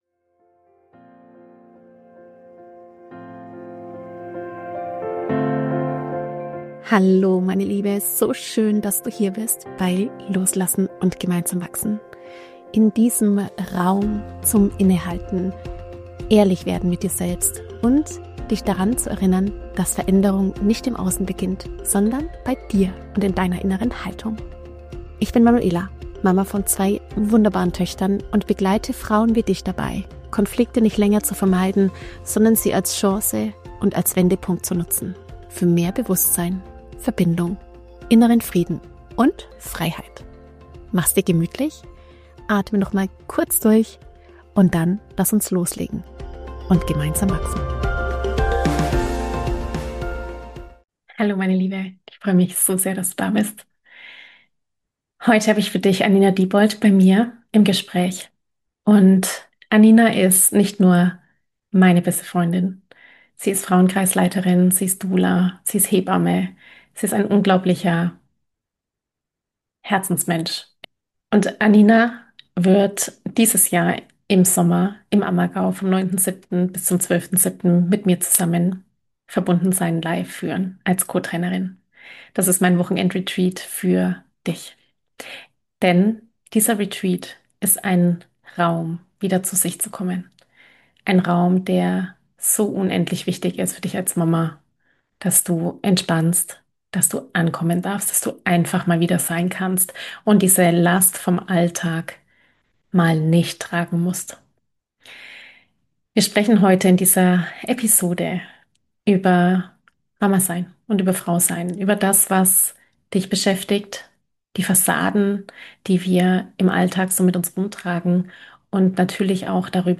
In diesem ehrlichen und ungeschönten Gespräch